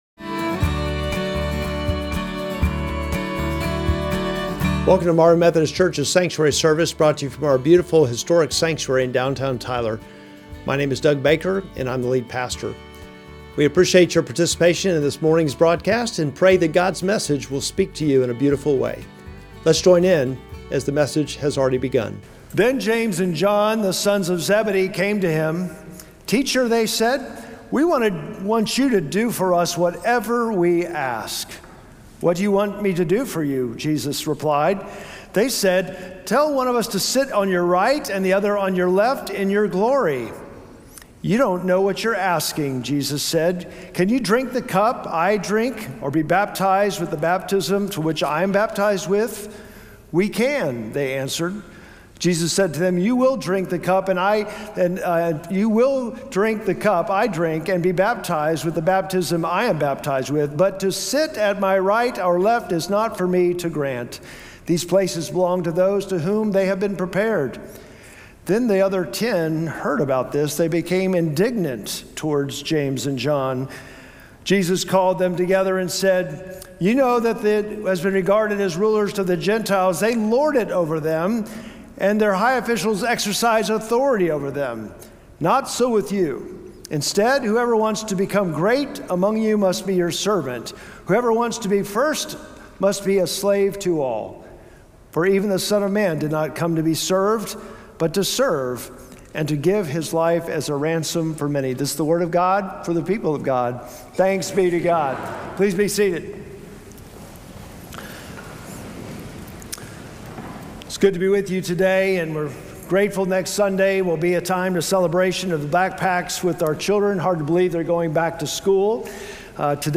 Sermon text: Mark 10:35-45